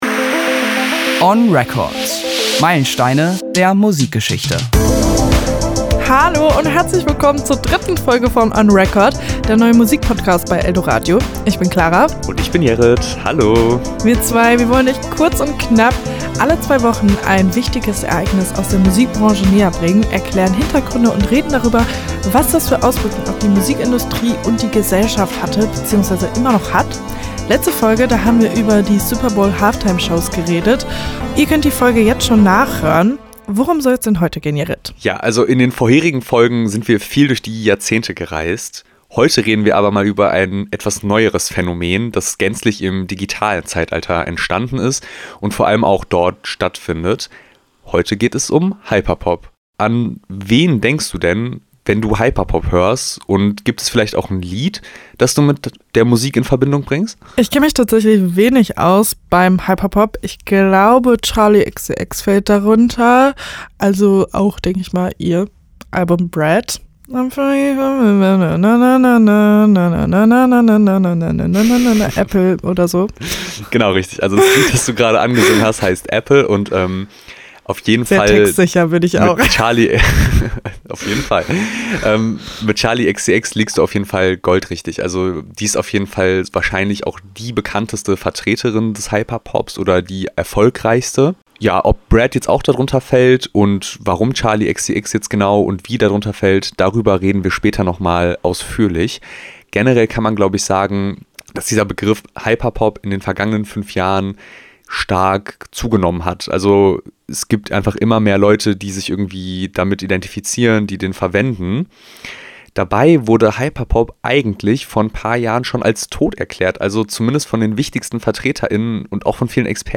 on_record_folge002_ohne_musik.mp3